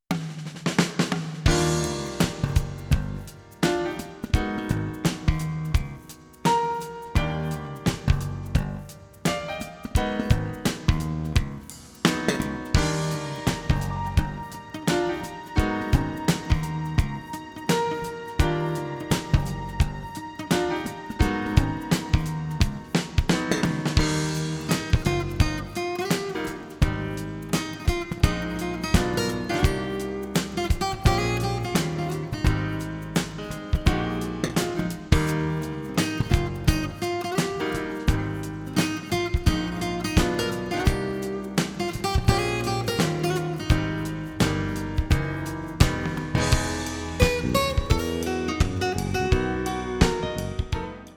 Håll i er, det är mässjazz!
¤ FM-15 är ca 0,5 Hz.